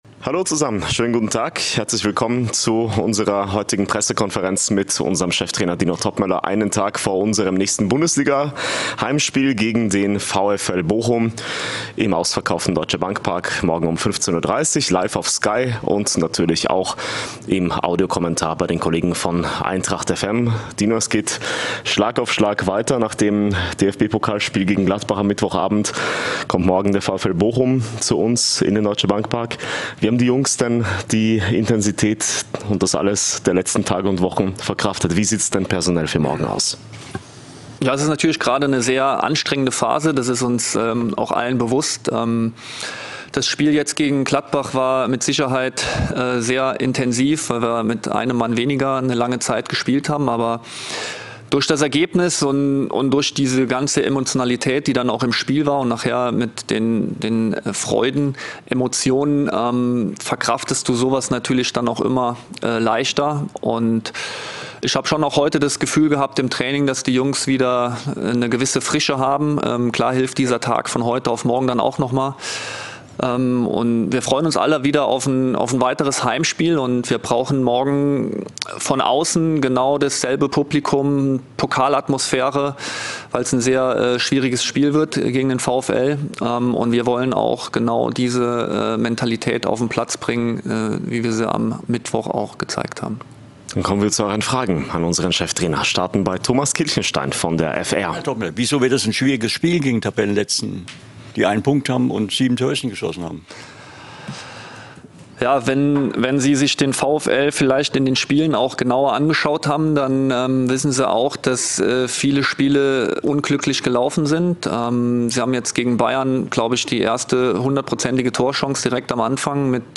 Die Pressekonferenz mit Cheftrainer Dino Toppmöller vor dem Bundesliga-Heimspiel gegen den VfL Bochum.